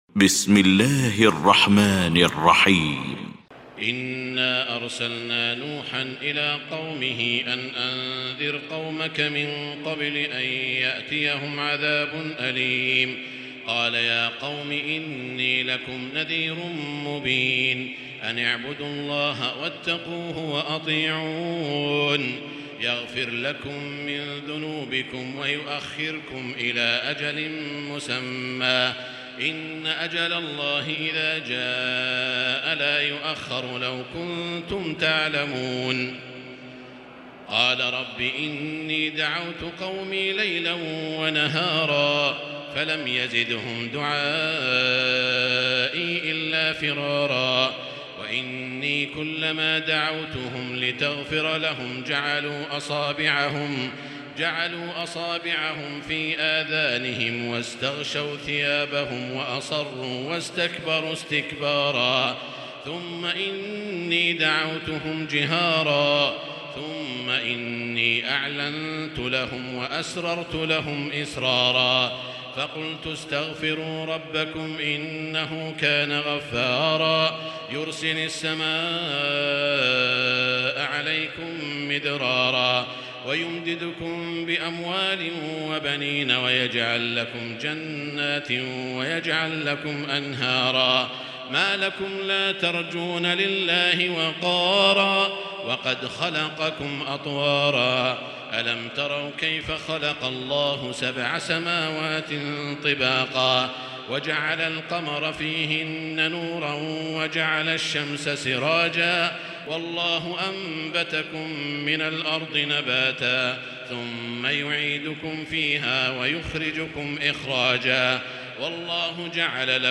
المكان: المسجد الحرام الشيخ: سعود الشريم سعود الشريم نوح